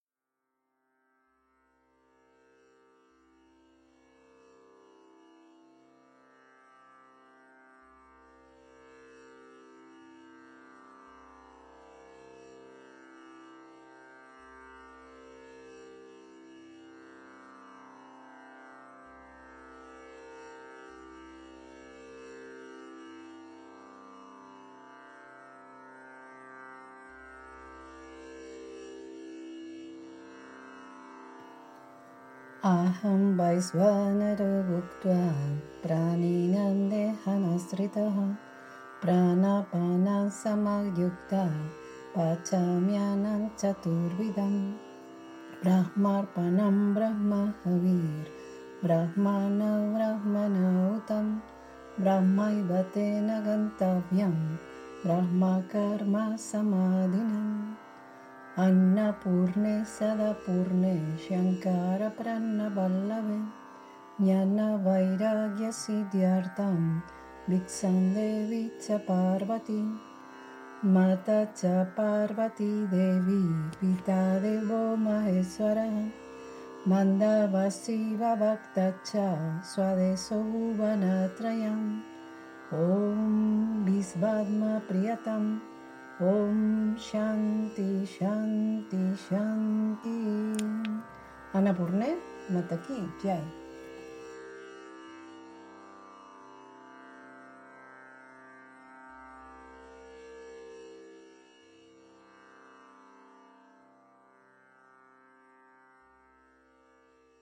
Hermosas recitaciones, mantras, versos, para agradecer el alimento que no es dado por Brahman.